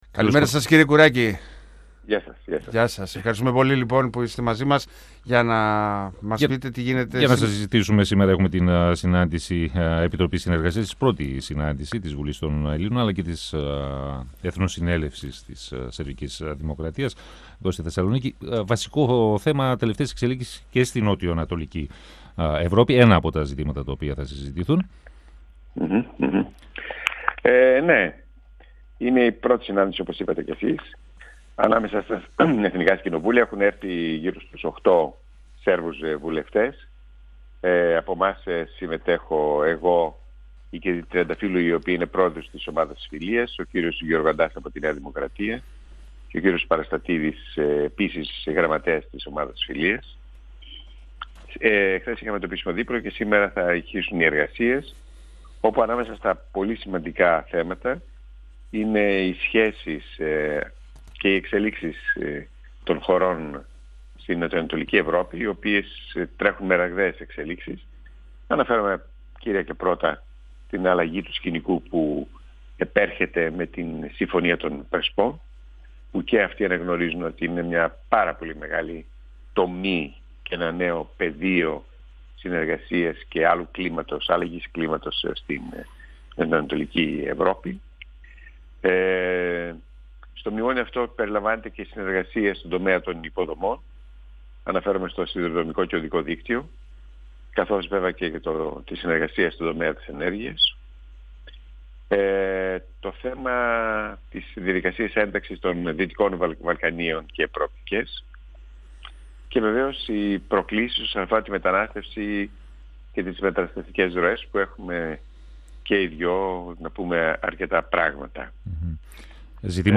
Ο Α’ αντιπρόεδρος της Βουλής Τάσος Κουράκης, στον 102FM του Ρ.Σ.Μ. της ΕΡΤ3
Η 1η συνάντηση επιτροπών συνεργασίας της ελληνικής Βουλής και της σερβικής Εθνοσυνέλευσης πραγματοποιείται σήμερα στη Θεσσαλονίκη. Στα θέματα που περιλαμβάνει η ατζέντα της συνάντησης αναφέρθηκε ο Α’ αντιπρόεδρος της Βουλής Τάσος Κουράκης, μιλώντας στον 102FM της ΕΡΤ3.